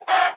chicken
hurt2.ogg